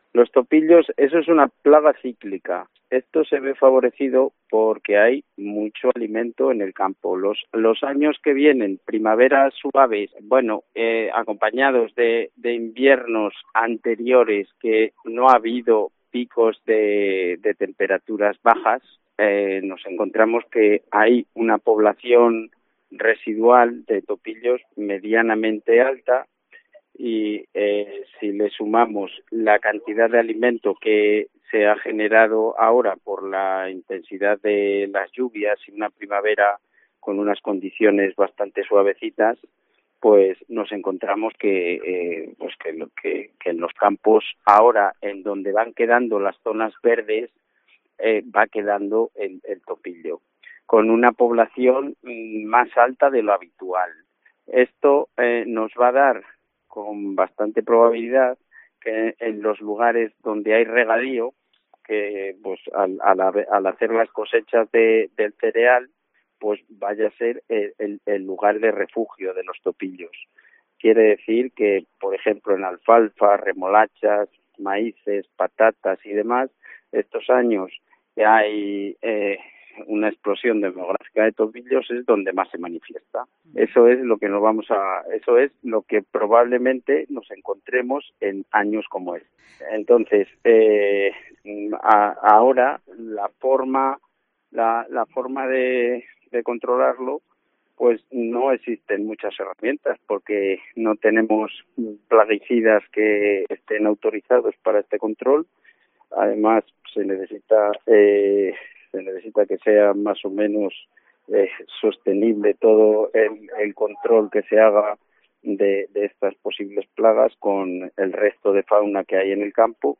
Cope Salamanca entrevista